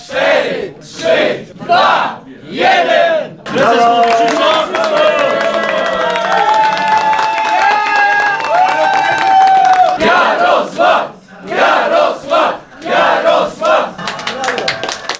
Informację o wynikach wyborów parlamentarnych w kraju działacze Prawa i Sprawiedliwości, zebrani w sztabie przy radomskim rynku, przyjęli okrzykami radości i gromkimi brawami.